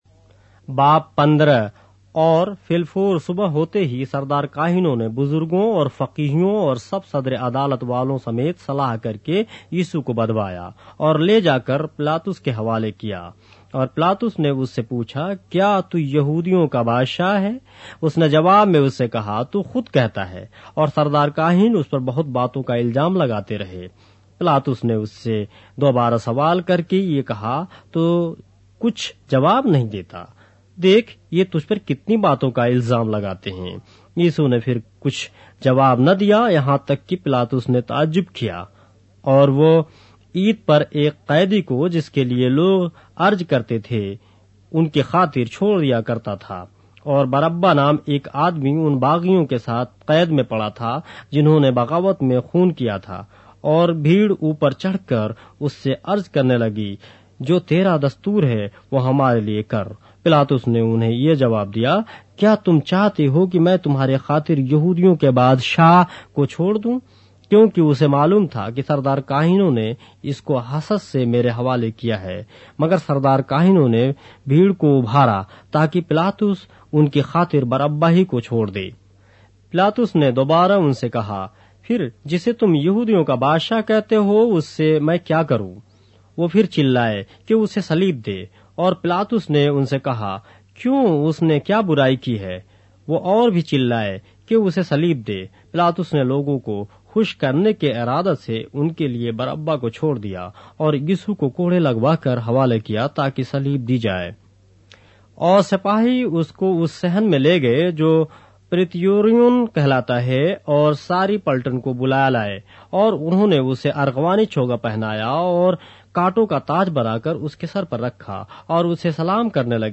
اردو بائبل کے باب - آڈیو روایت کے ساتھ - Mark, chapter 15 of the Holy Bible in Urdu